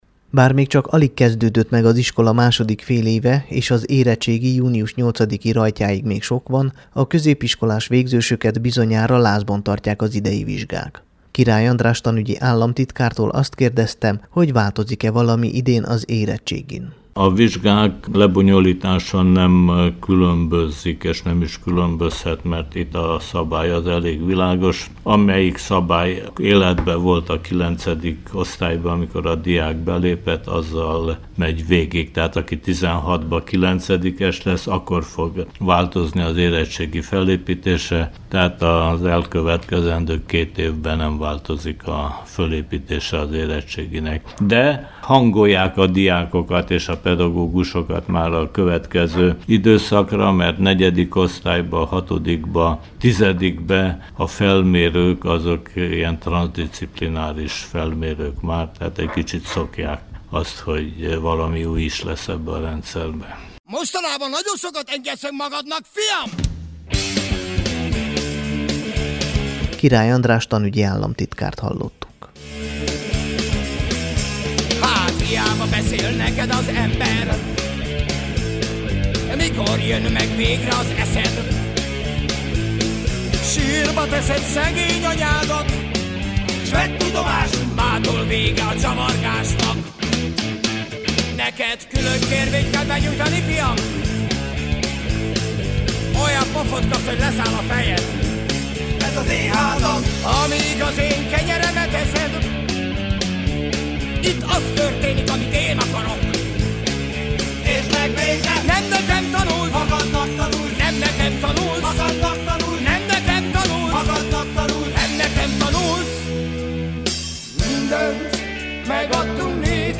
Király András a Temesvári Rádió szerdai ifjúsági műsorában nyiatkozott.